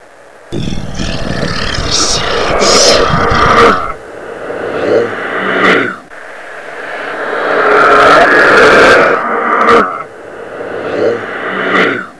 deadsignal_goaway.wav